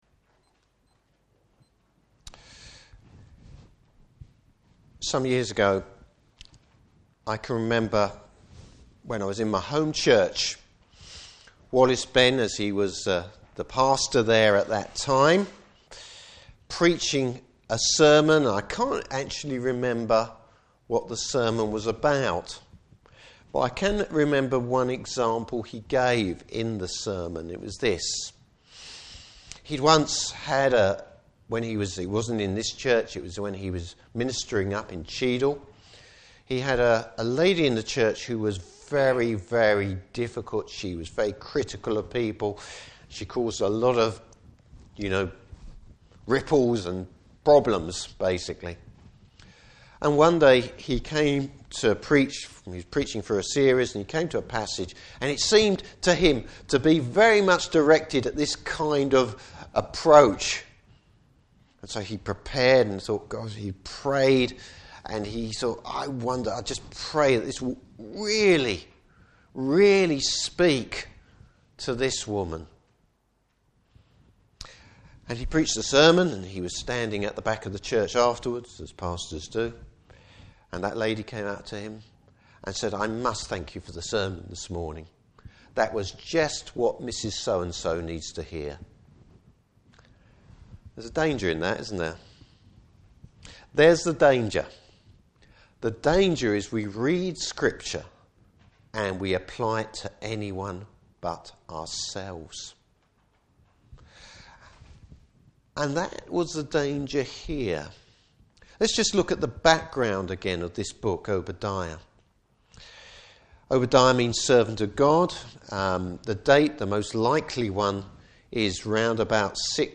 Service Type: Morning Service Bible Text: Obadiah 15-21.